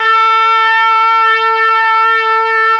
RED.BRASS 27.wav